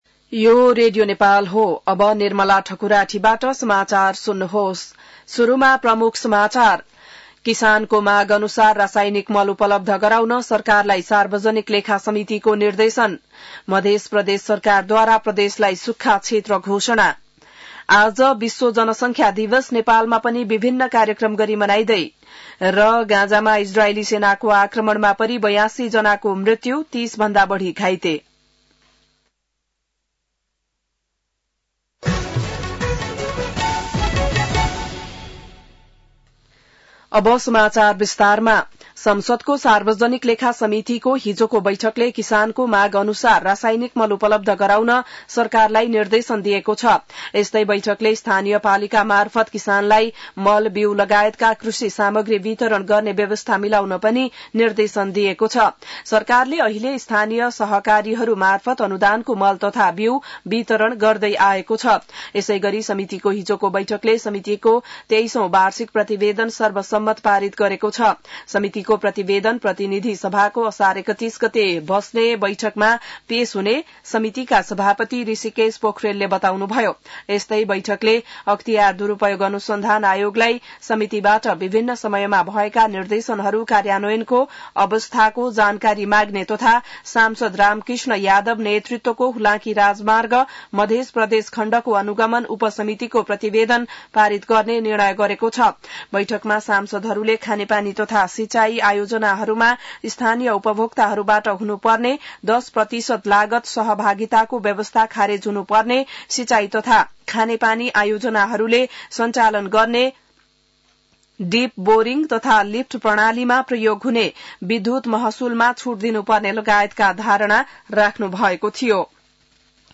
An online outlet of Nepal's national radio broadcaster
बिहान ९ बजेको नेपाली समाचार : २७ असार , २०८२